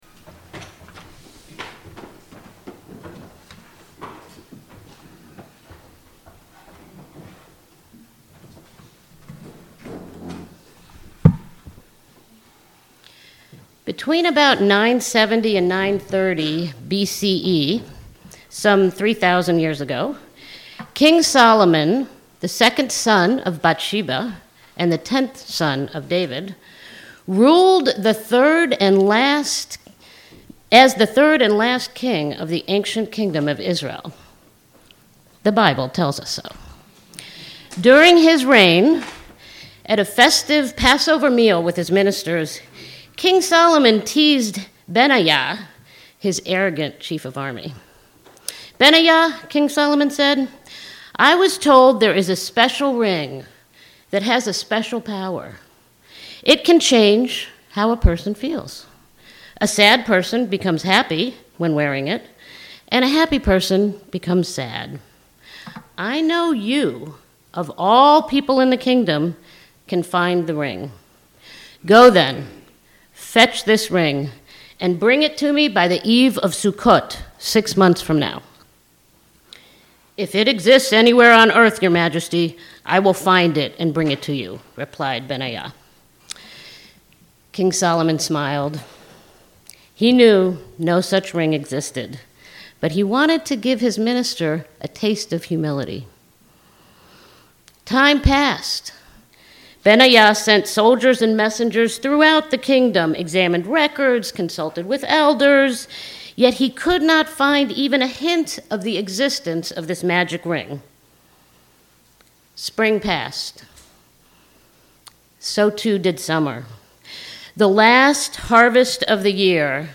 This sermon is centered on the ancient Jewish fable of King Solomon’s ring, which bore the inscription “This too shall pass.”